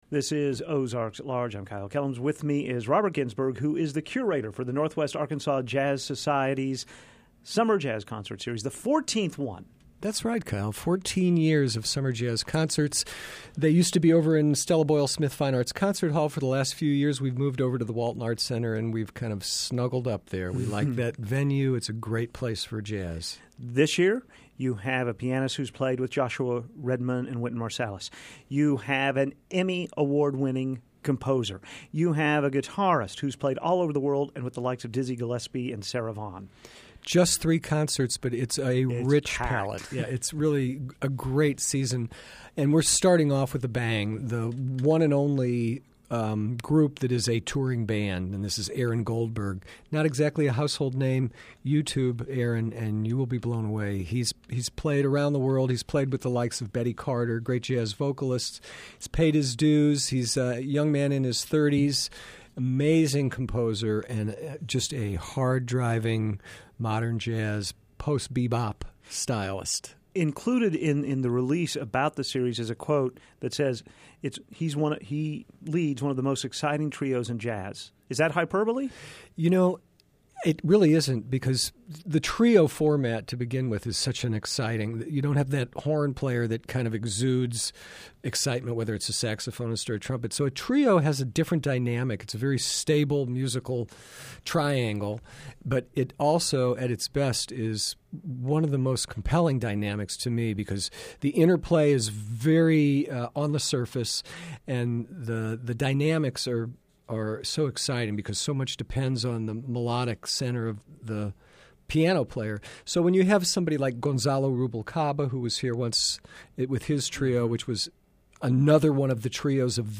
Music included in the story: “Oam’s Blues” by Aaron Goldberg “Moon and Sand” by Vic Juris